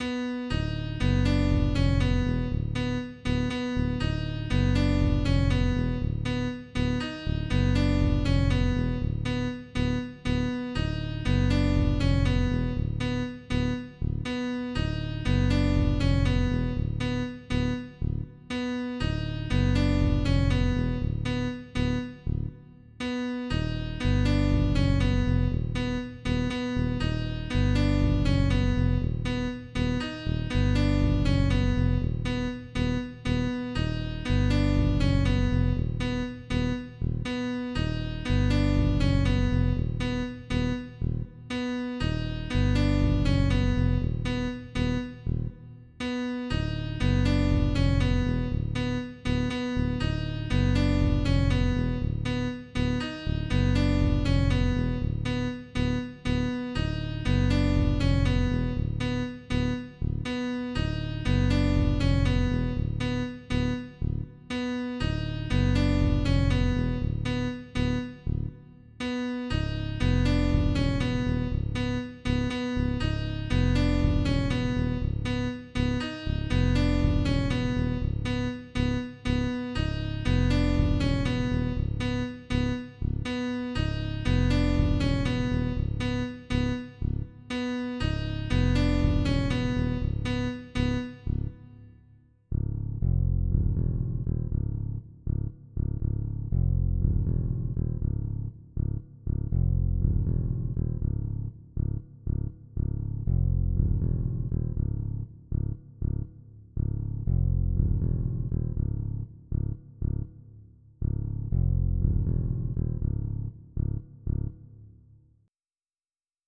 • Качество: 320, Stereo
электронная музыка
без слов
клавишные
пианино